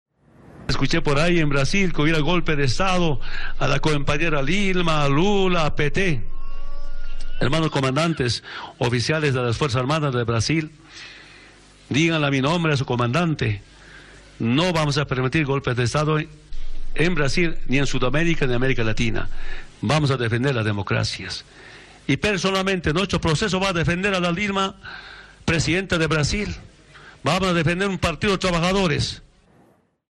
O Presidente Boliviano Evo Morales celebrou hoje os 115 anos de criação da Escola Militar de Sargentos Maximiliano Paredes, localizado na cidade de Tarata, Cochabamba.
VOZ DE EVO MORALES